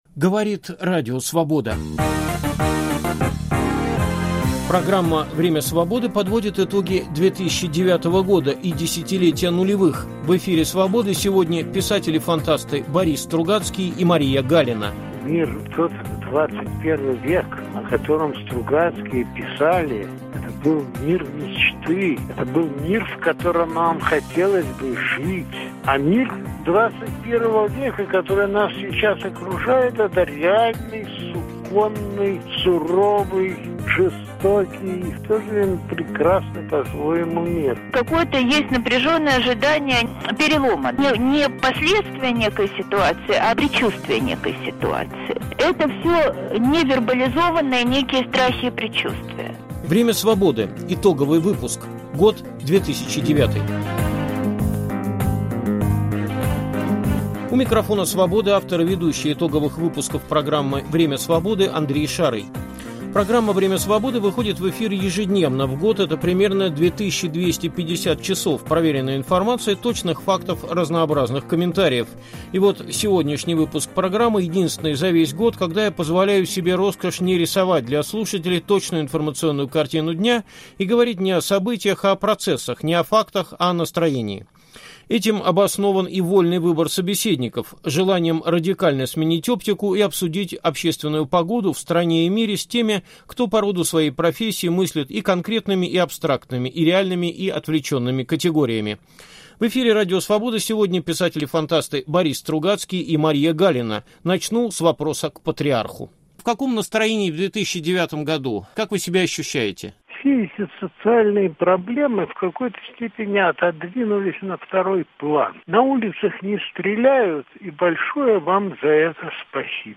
В эфире Радио Свобода писатели-фантасты Борис Стругацкий и Мария Галина подводили итоги уходящего 2009 года и делились с радиослушателями своими мыслями о том, как будет выглядеть будущее. В том числе - о том, почему его четкие контуры определить невозможно.